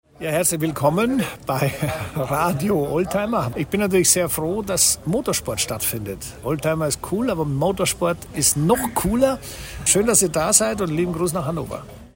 netter Gruß von Christian Danner, klick!
Nuerburgring_24h_-_Christian_Danner_Gruss.MP3